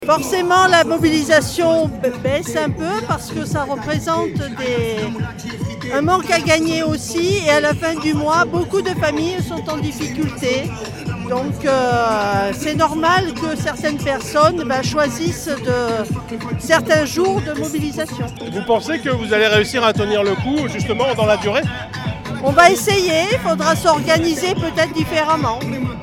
son-infirmiere-1-9321.mp3